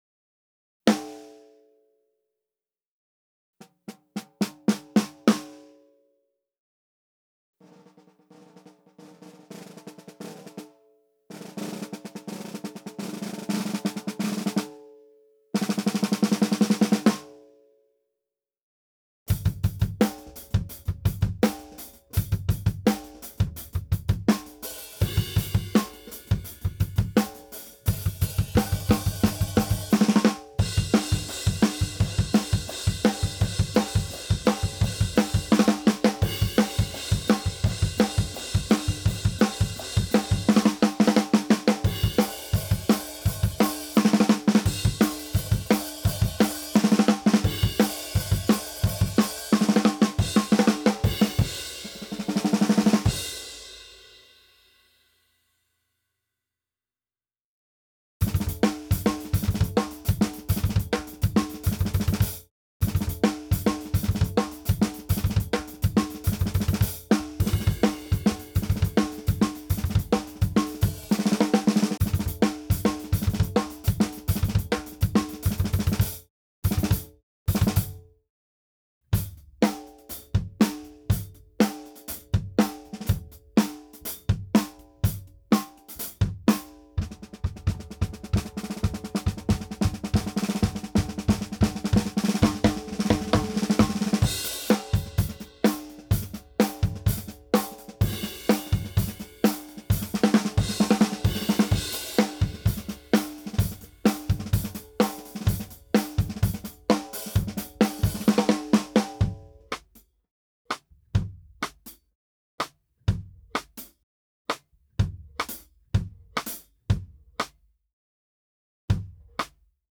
The Big Power, Sensitive Tone!!
Black brushed Steel Shell
If you're in need of a drum that a hack its way thought the thickest of guitar noise, look no future.
シャープなトラディショナルベアリングエッジによる驚異のヴォリュームと抜けの良さが特徴です。新開発のソニックセーバーフープの効果によるロングサスティーンと綺麗な倍音が一層メタルスティール素材の美味しい所を十分満喫して頂ける会心の出来です。太くてディストーションの掛かったギターにも埋もれない力強いトーン。